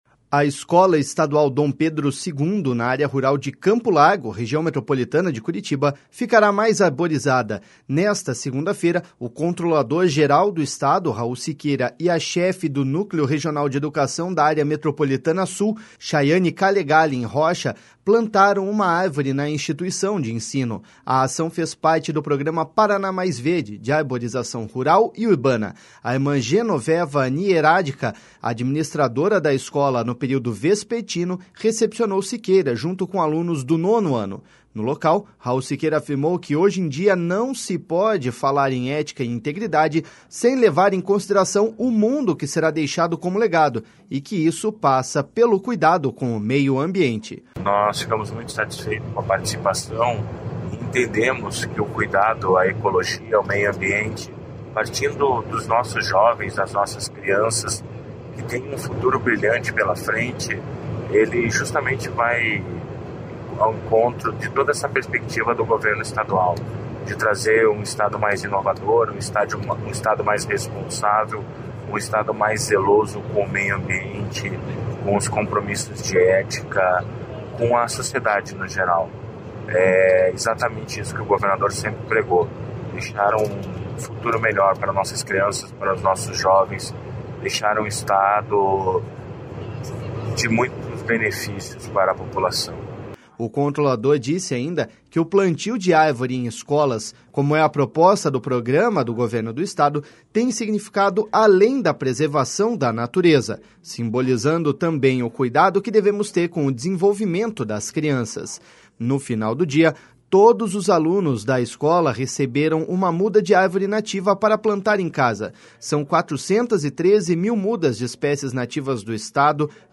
No local, Raul Siqueira afirmou que, hoje em dia, não se pode falar em ética e integridade sem levar em consideração o mundo que será deixado como legado, e que isso passa pelo cuidado com o meio ambiente.// SONORA RAUL SIQUEIRA.//